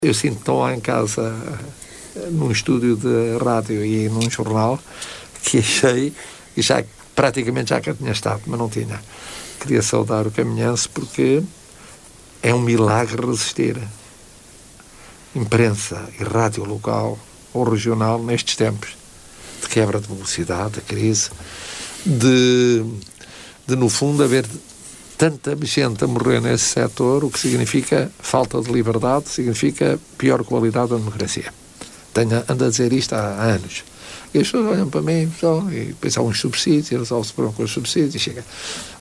A afirmação foi feita ontem pelo presidente da República, Marcelo Rebelo de Sousa, aos microfones da Rádio Caminha no decorrer da conversa que manteve com jovens do concelho.